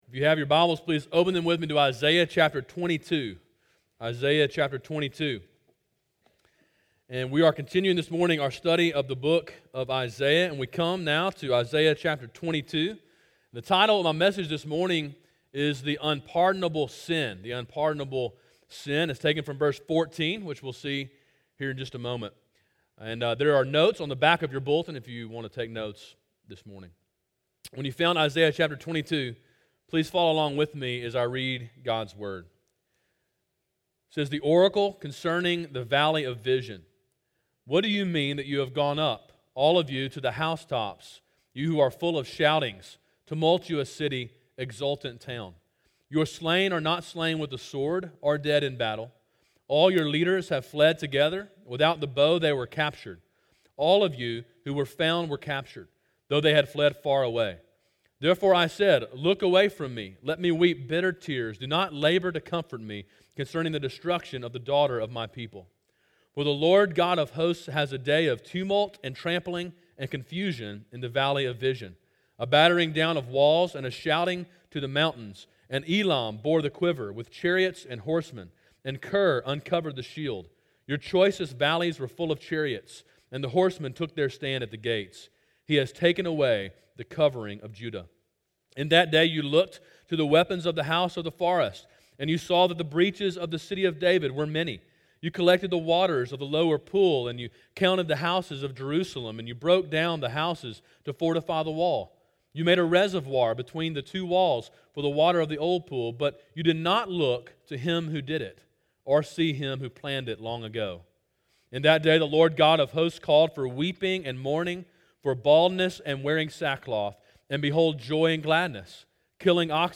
Sermon in a series on the book of Isaiah.